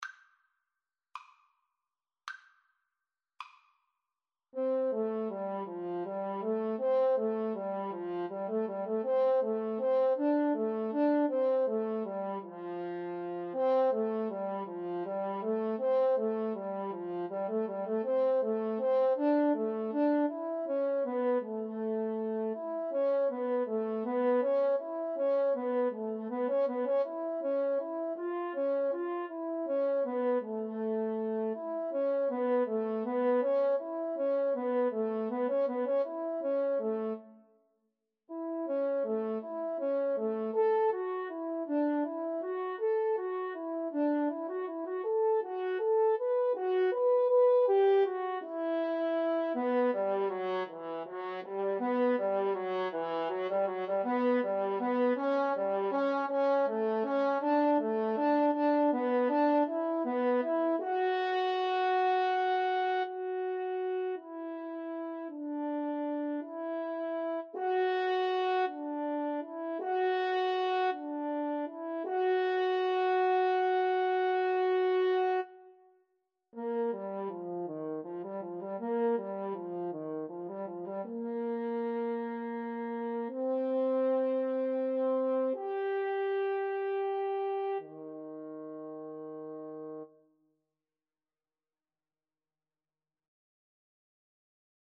Andante
6/8 (View more 6/8 Music)